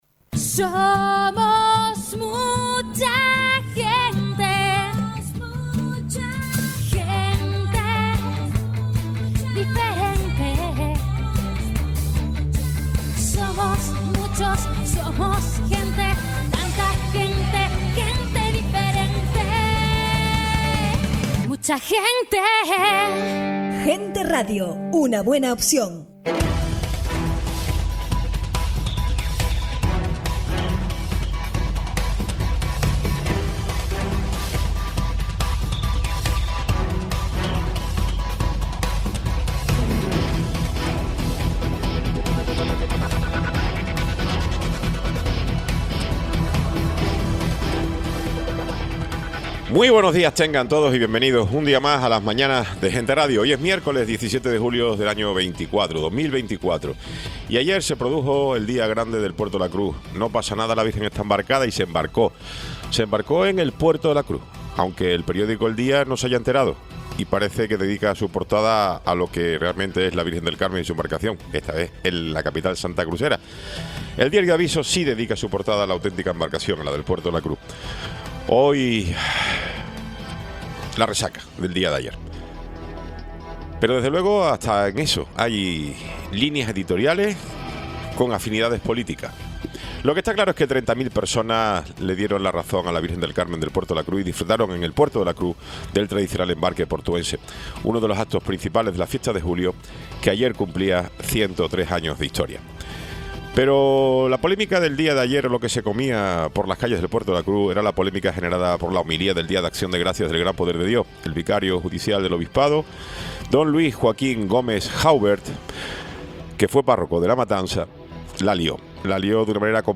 Tiempo de entrevista con José Caro, concejal de Servicios Sociales y 2º Teniente de Alcalde del Ayto. de Tacoronte
Tertulia